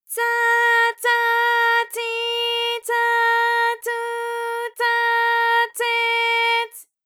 ALYS-DB-001-JPN - First Japanese UTAU vocal library of ALYS.
tsa_tsa_tsi_tsa_tsu_tsa_tse_ts.wav